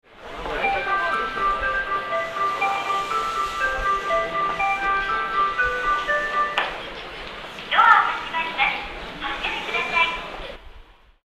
otsuka_1melody.mp3